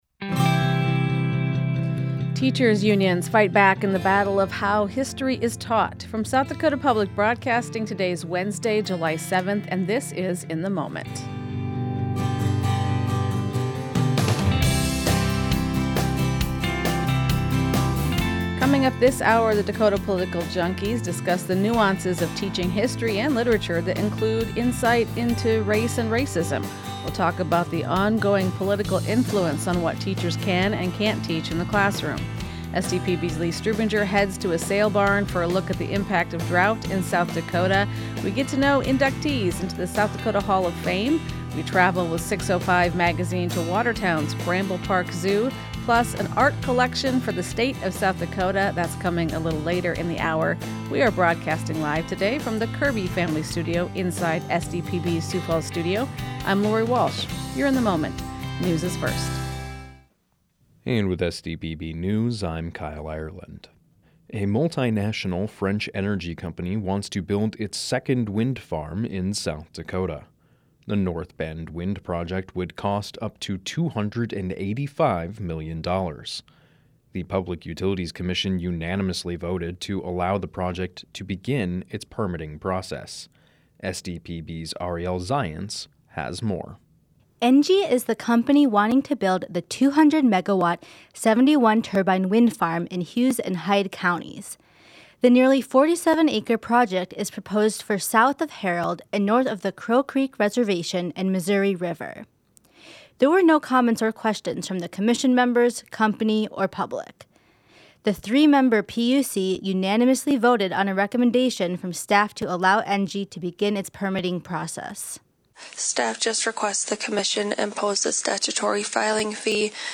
In the Moment is SDPB’s daily news and culture magazine program.